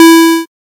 safe-1.ogg.mp3